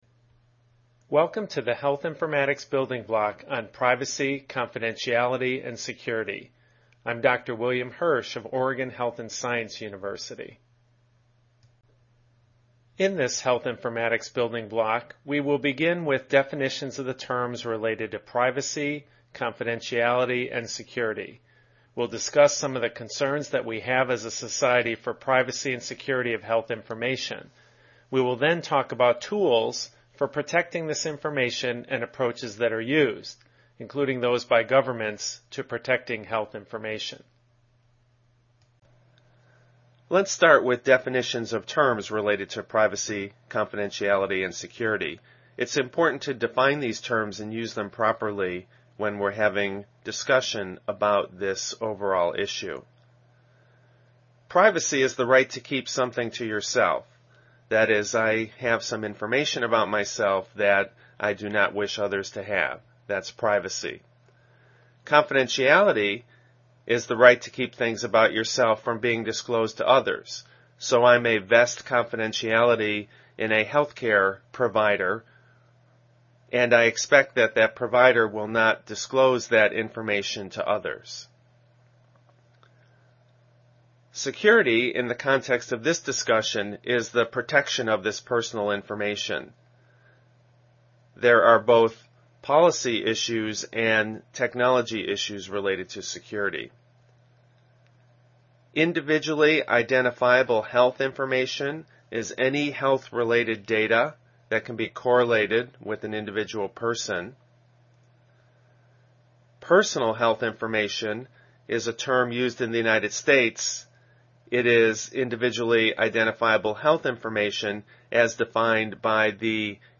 Privacy, Confidentiality, and Security Lecture Audio